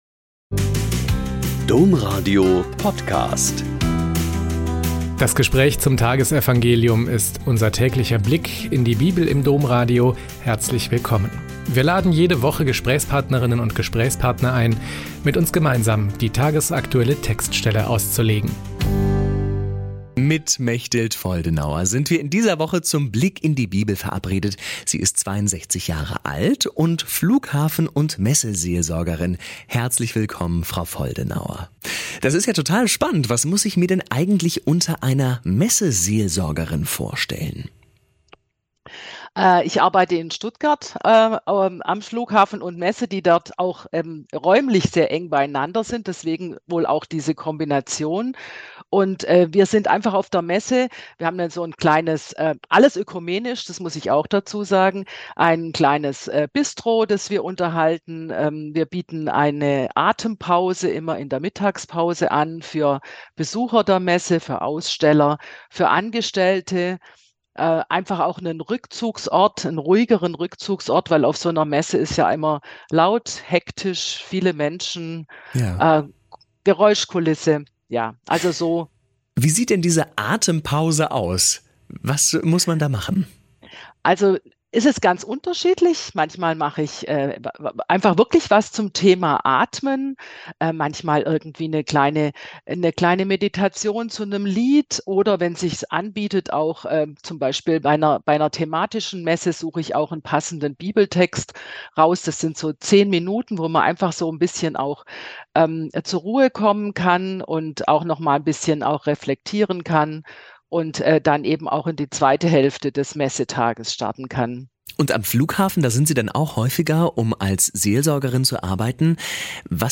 Mt 17,22-27 - Gespräch